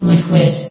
CitadelStationBot df15bbe0f0 [MIRROR] New & Fixed AI VOX Sound Files ( #6003 ) ...
liquid.ogg